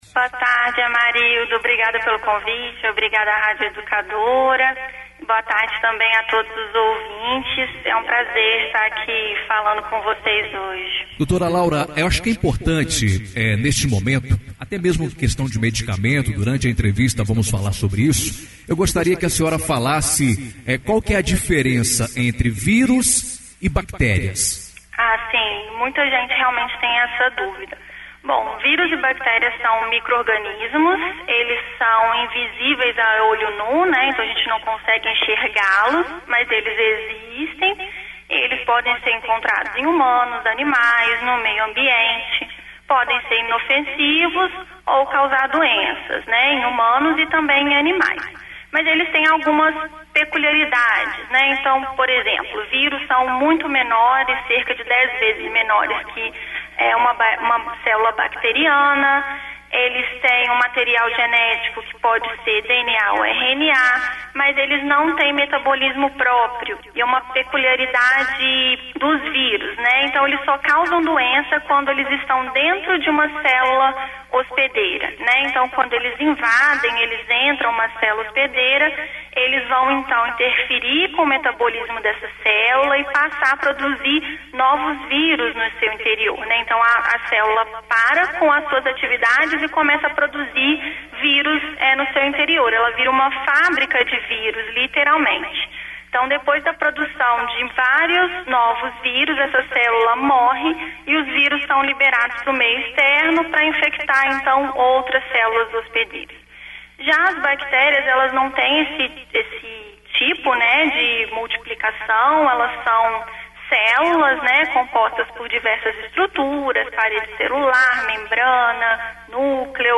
Em entrevista
na Rádio Educadora